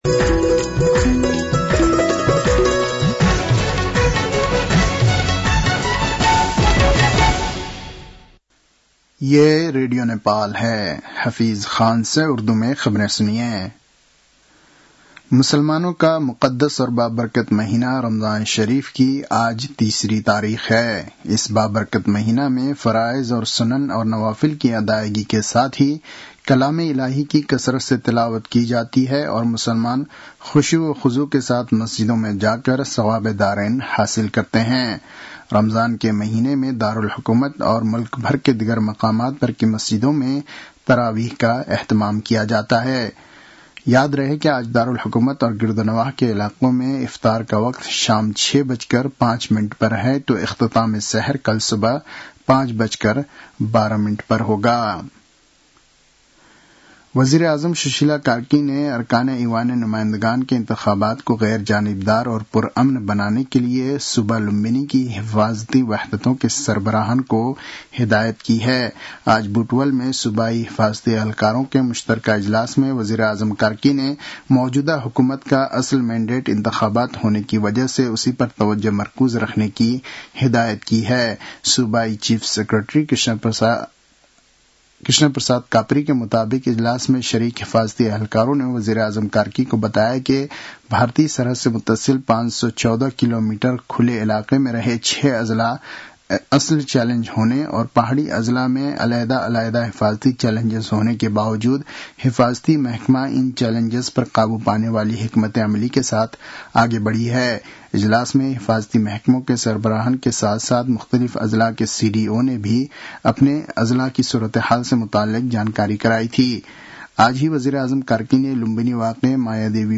An online outlet of Nepal's national radio broadcaster
उर्दु भाषामा समाचार : ९ फागुन , २०८२